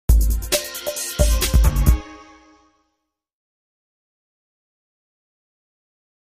Music Logo; Short Dance Groove Beat, With A Hi-tech Feel.